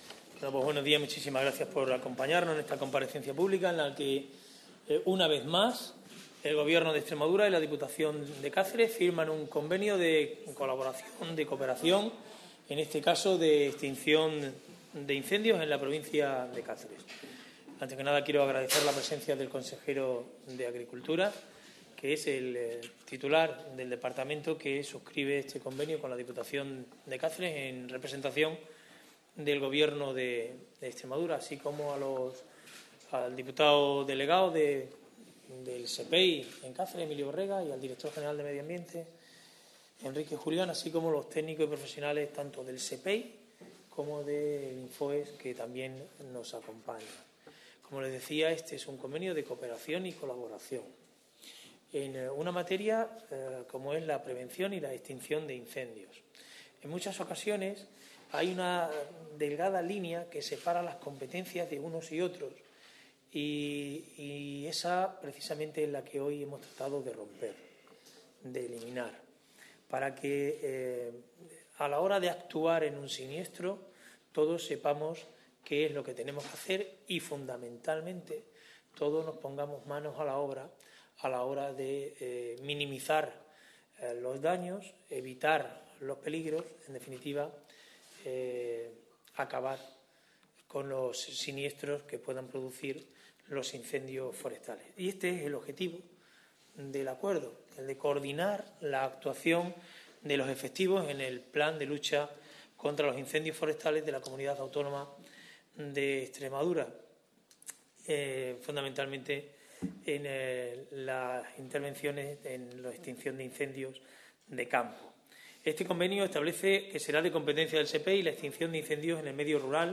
CORTES DE VOZ
AUDIO_FIRMA_CONVENIO_SEPEI-INFOEX.mp3